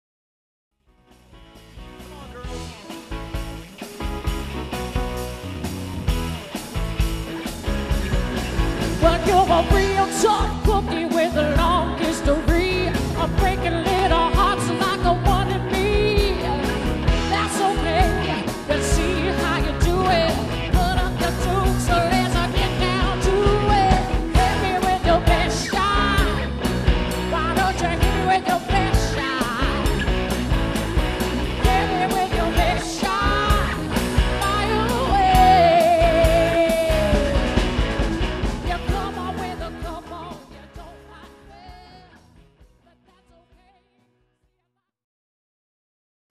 80's, 90's & 00's Music
Adds A Female Vocalist